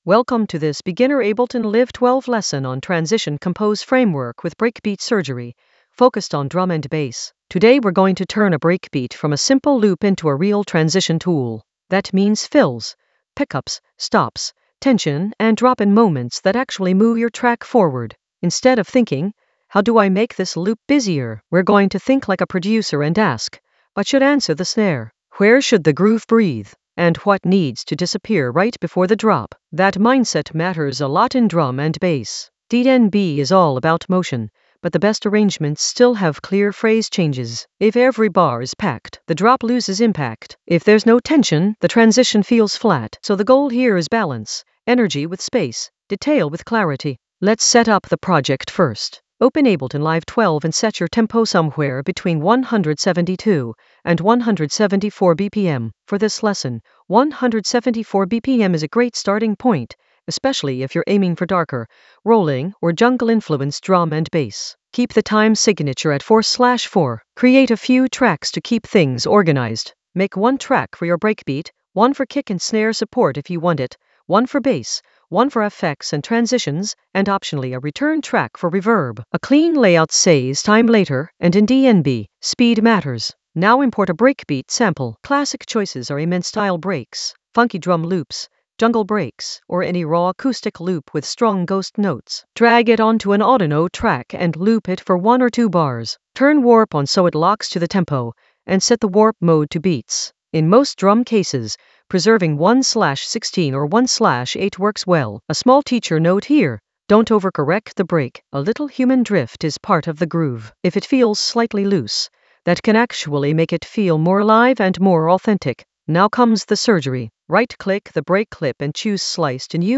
An AI-generated beginner Ableton lesson focused on Transition compose framework with breakbeat surgery in Ableton Live 12 in the Breakbeats area of drum and bass production.
Narrated lesson audio
The voice track includes the tutorial plus extra teacher commentary.